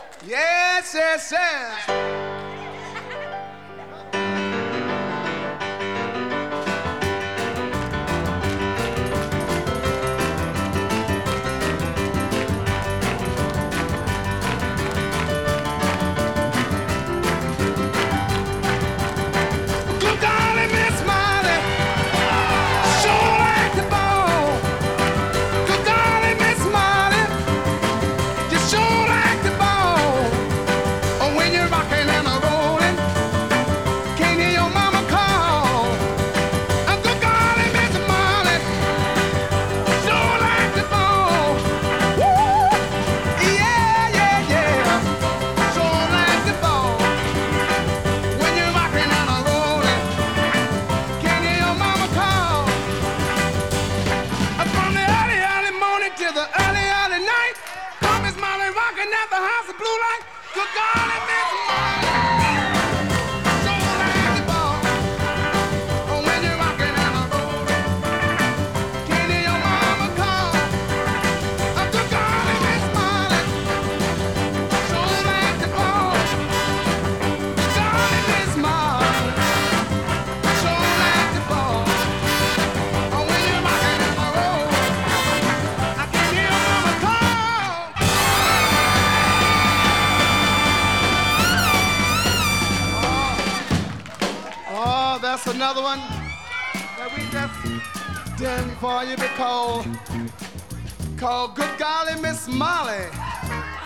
Жанр Рок-н-ролл, госпел